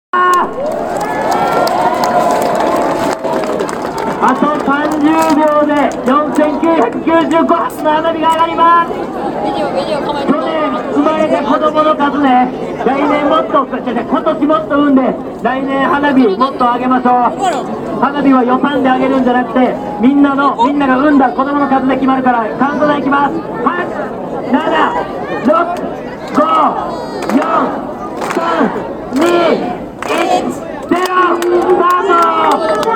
CountDown（第一回 水都くらわんか花火大会）